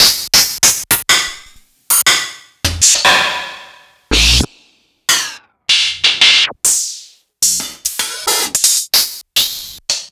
011_Lost Tapes From Mars_Open Hat.wav